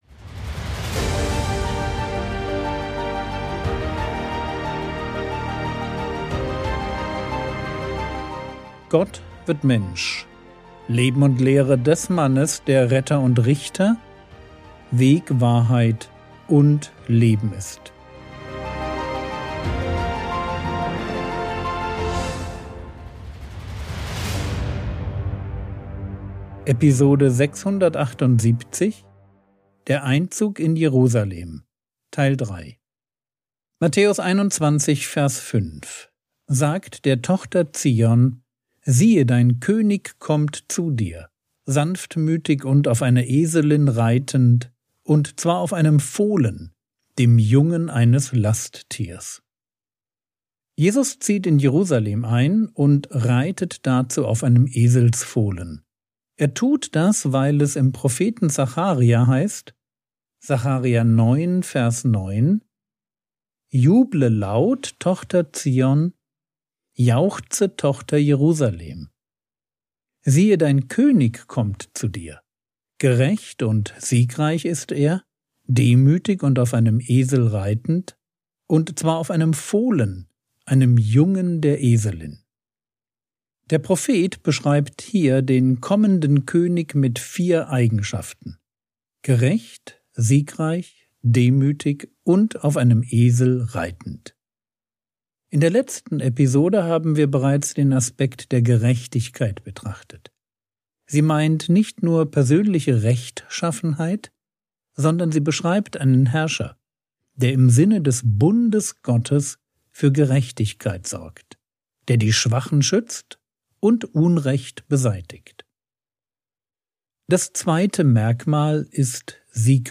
Episode 678 | Jesu Leben und Lehre ~ Frogwords Mini-Predigt Podcast